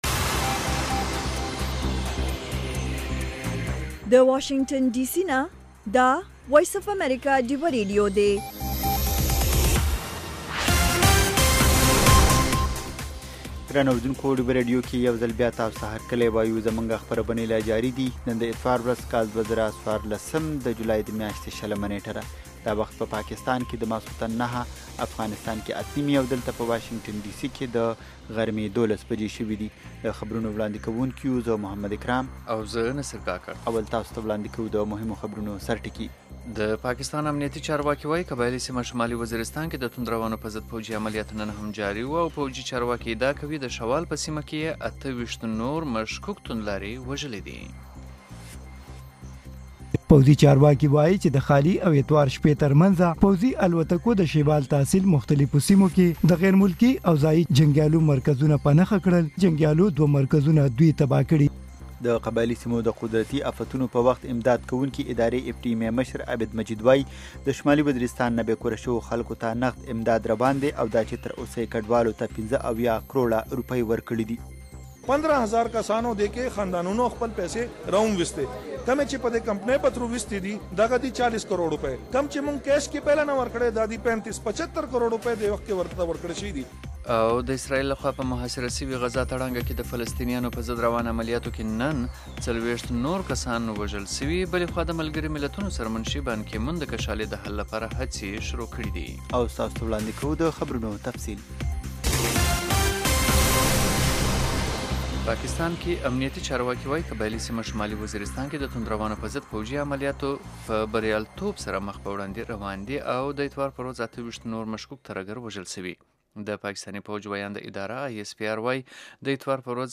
دا یو ساعته خپرونه خونده ورې سندرې لري میلمانه یې اکثره سندرغاړي، لیکوالان، شاعران او هنرمندان وي. مهال ويش هره ورځ